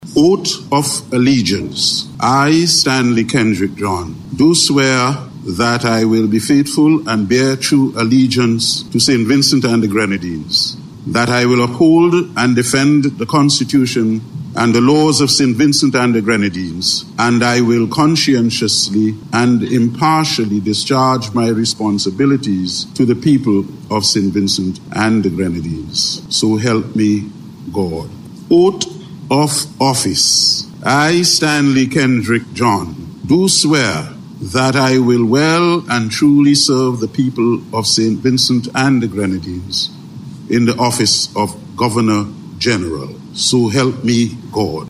The ceremony, held at Government House, included the administration of the Oath of Allegiance and the Oath of Office.
The voice of Governor General, His Excellency Stanley Kendrick John, KC was officially installed as this country’s 8th Governor General earlier today.